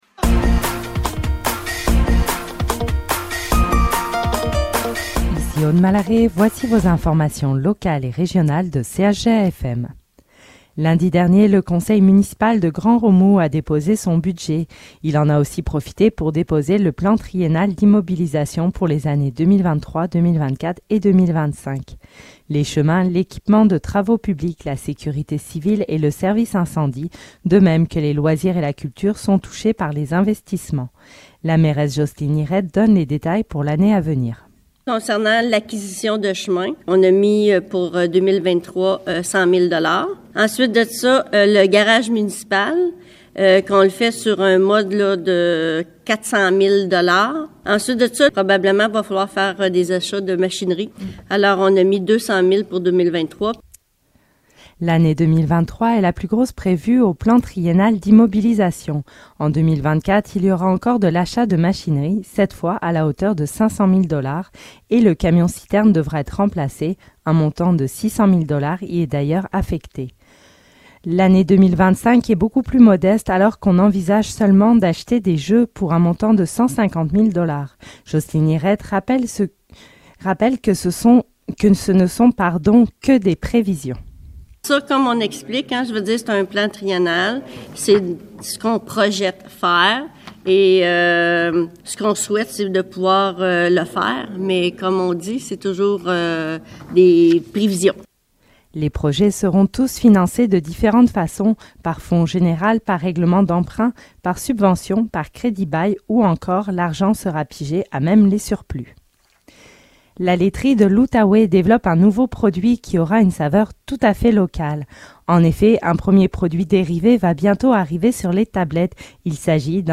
Nouvelles locales - 21 décembre 2022 - 15 h